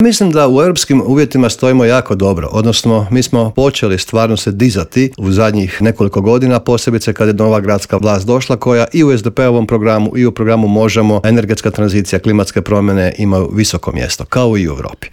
ZAGREB - Povodom međunarodne Konferencije "Zelena tranzicija pokreće europske gradove" predsjednik Gradske skupštine Grada Zagreba Joško Klisović gostovao je Intervjuu Media servisa.